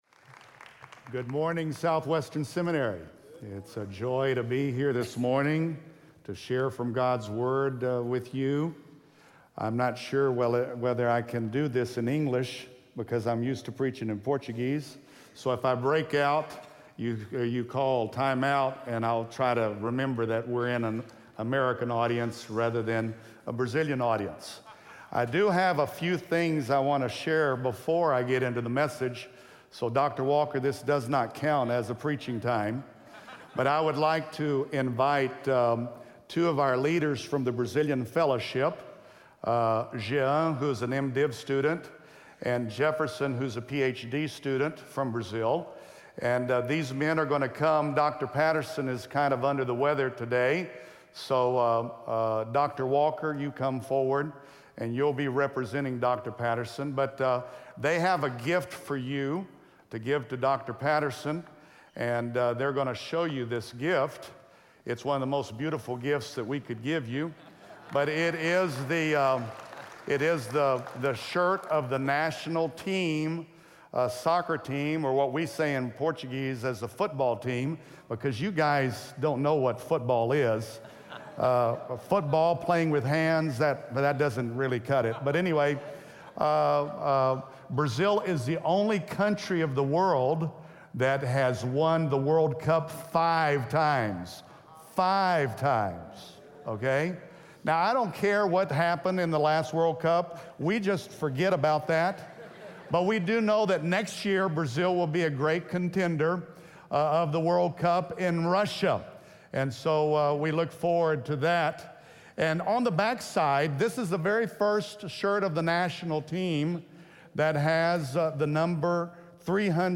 SWBTS Chapel Sermons